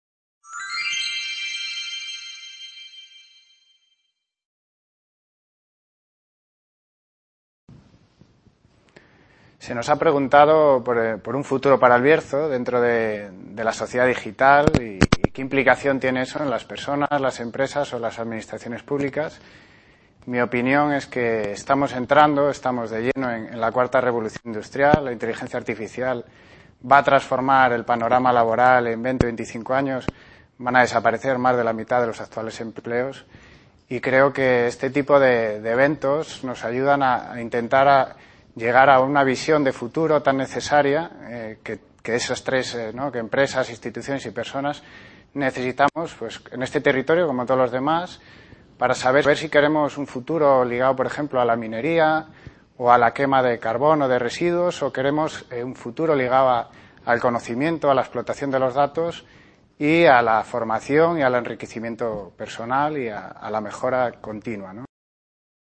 VI Edición del Festival Villar de los Mundos - El PASADO de LOS BARRIOS y el FUTURO de EL BIERZO
Video Clase